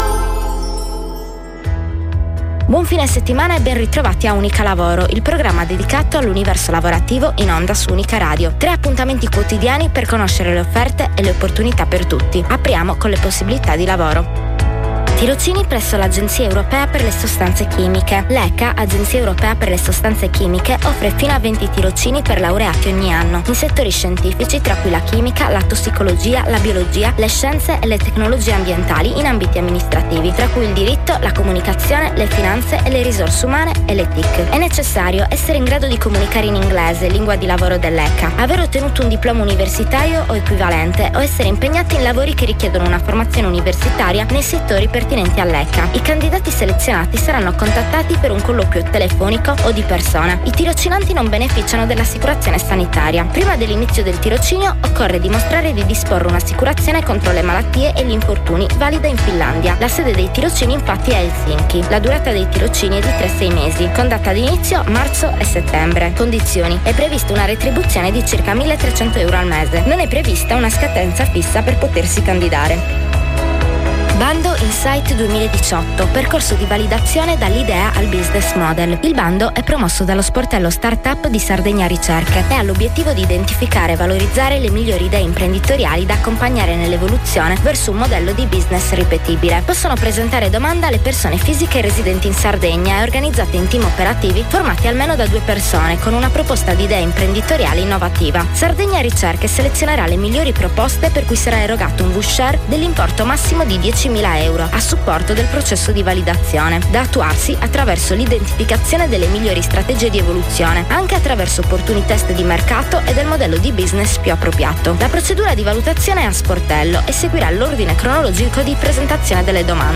Tre incontri quotidiani alle ore 8,  15 e 21 in onda su Unica Radio